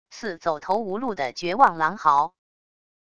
似走投无路的绝望狼嚎wav音频